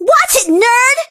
bibi_hurt_vo_04.ogg